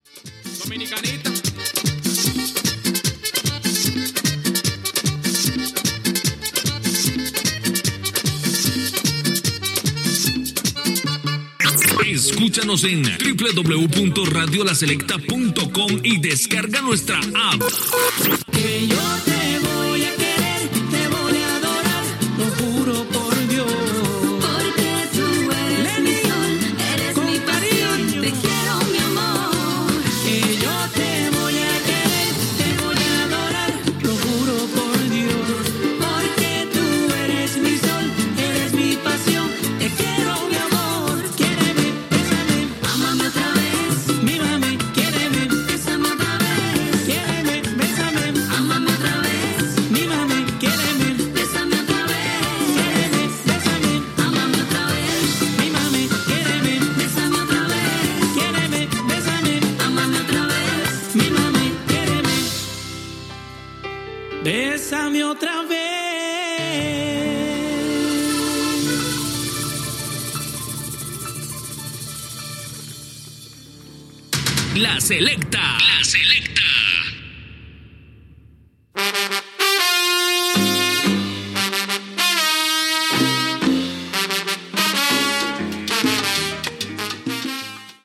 Temes musicals i indicatius